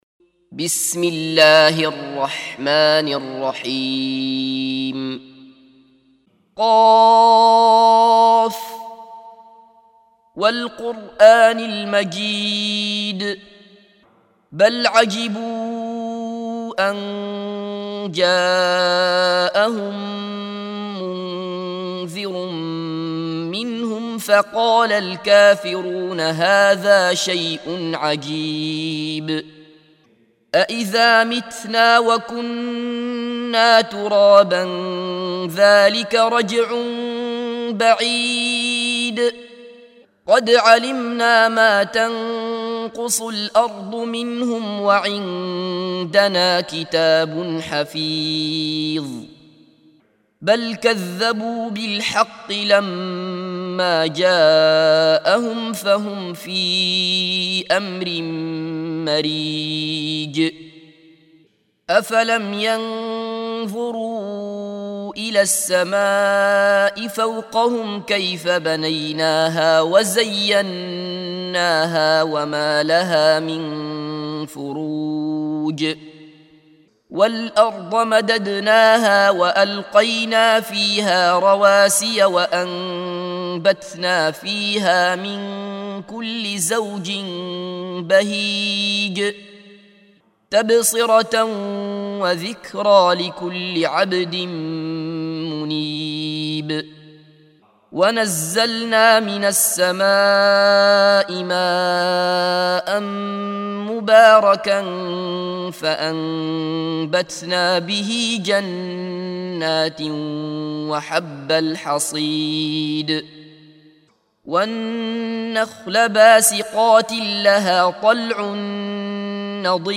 سُورَةُ ق بصوت الشيخ عبدالله بصفر